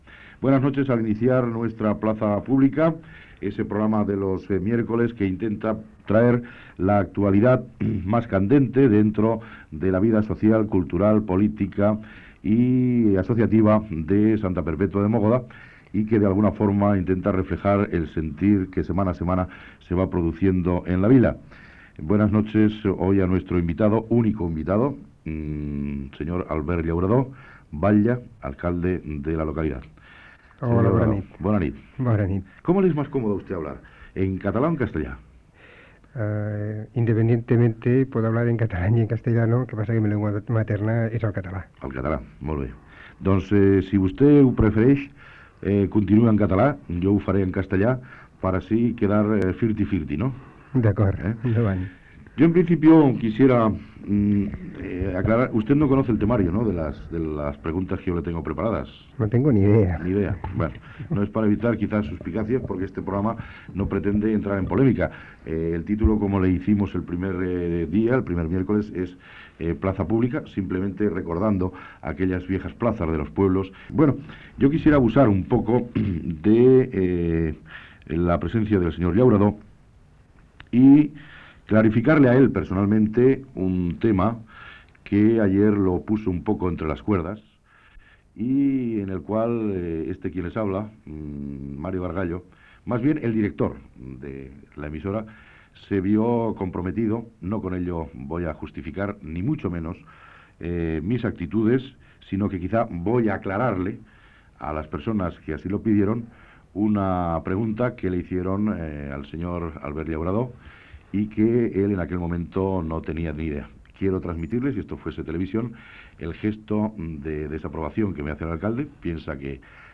Fragment procedent d'unes bobines magnetofòniques